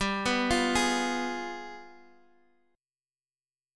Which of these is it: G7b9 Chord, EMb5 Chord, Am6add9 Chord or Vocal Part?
G7b9 Chord